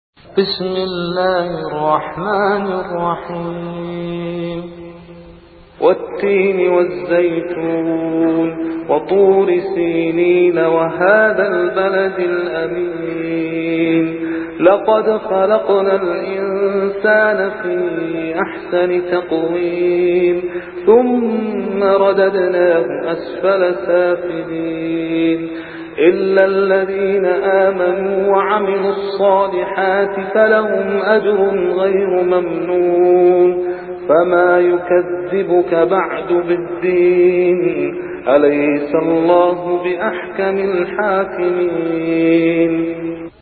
• Quran